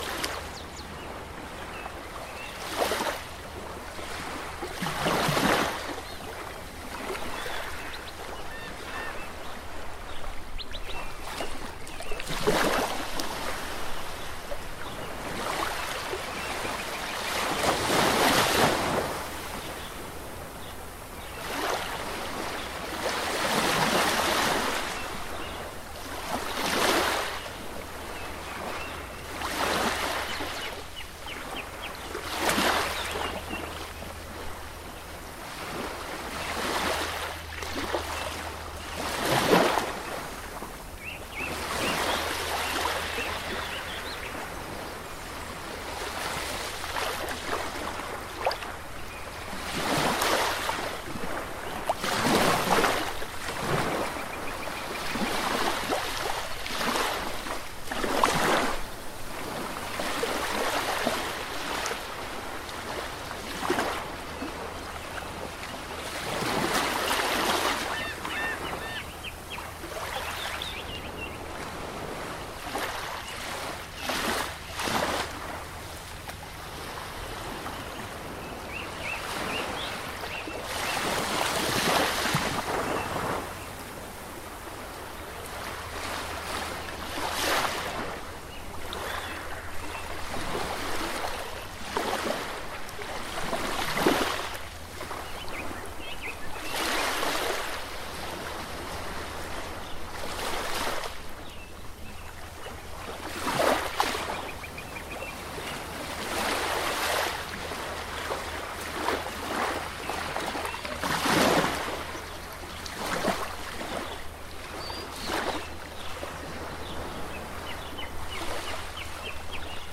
ocean-1.ogg